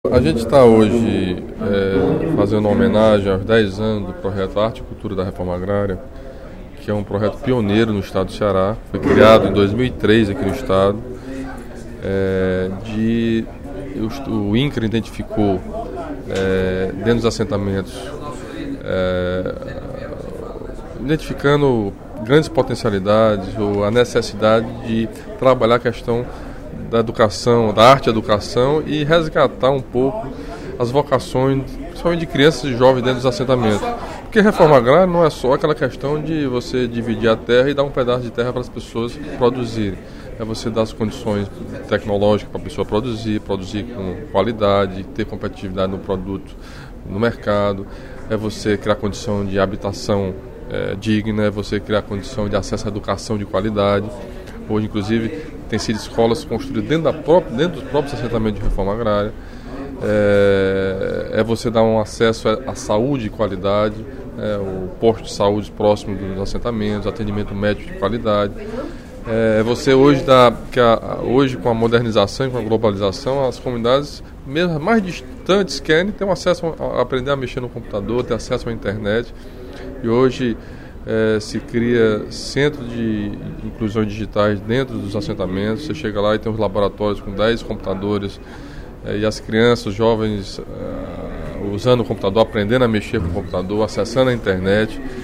O deputado Camilo Santana (PT) ocupou a tribuna do Plenário 13 de Maio no primeiro expediente da sessão plenária desta quinta-feira (05/12) para homenagear os dez anos do Projeto Arte e Cultura, do Instituto Nacional de Colonização e Reforma Agrária (Incra).
Em aparte, o deputado Tino Ribeiro (PSDC) destacou a importância das ações governamentais que beneficiem o homem do campo.